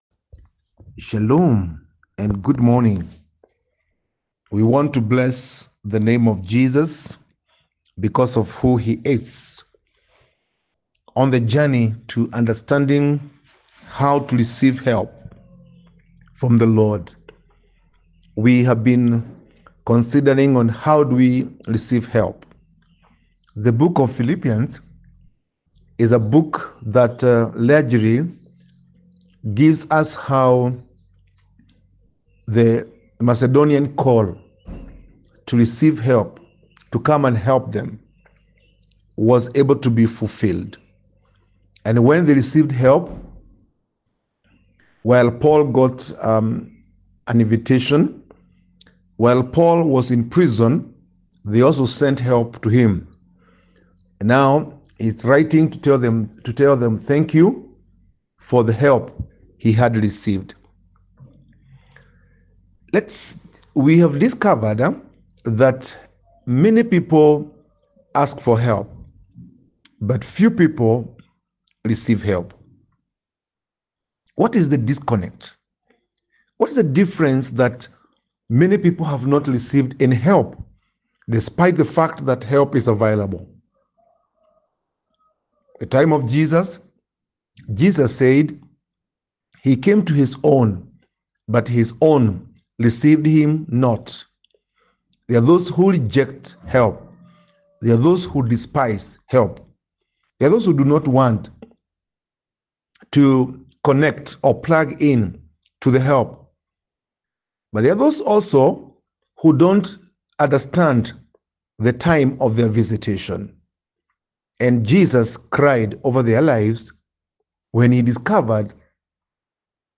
Audio Summary